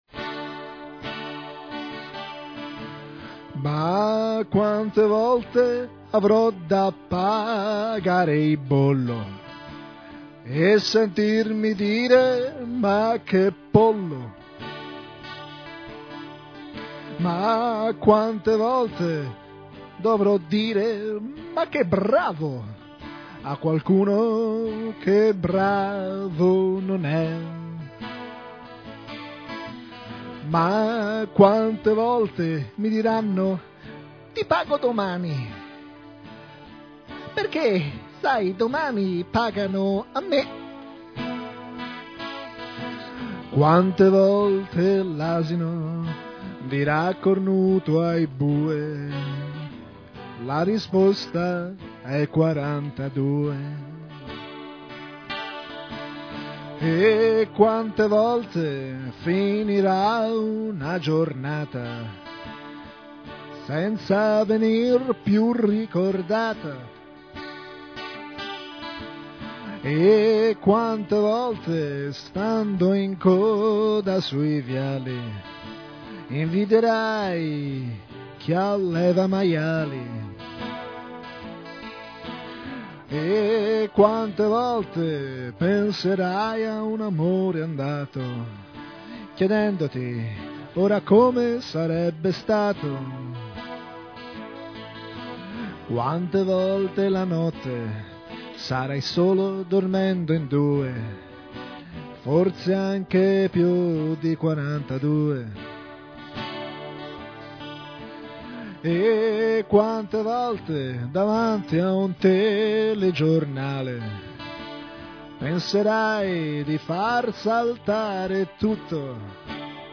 Un brano dolceamaro